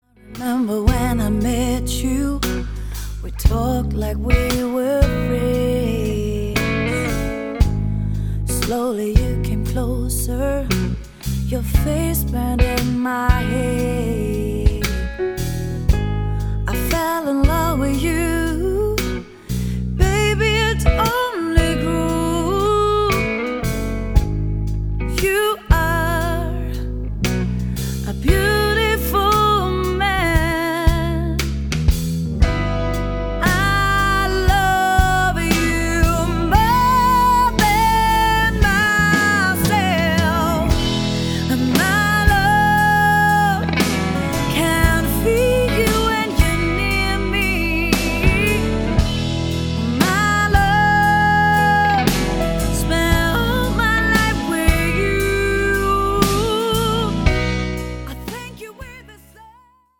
vocals
guitars, acoustic guitar
keyboards, piano
bass, background vocals
drums